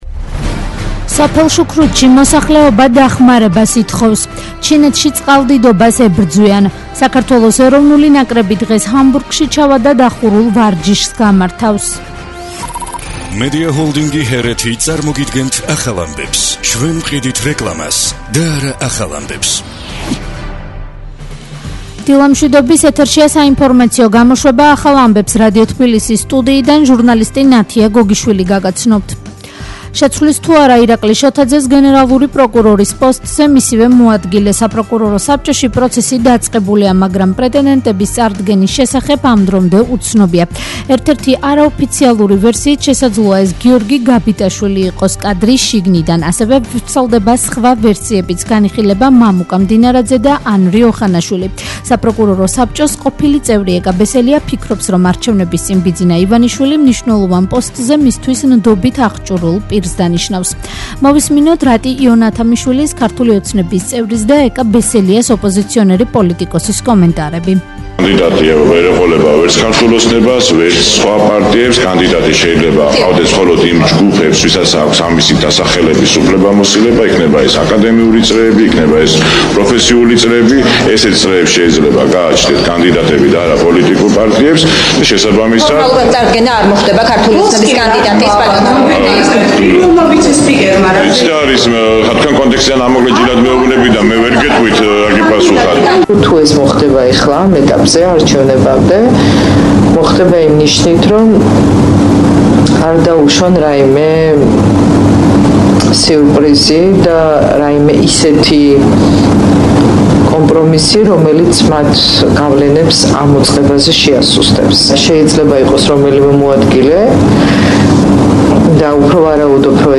ახალი ამბები 11:00 საათზე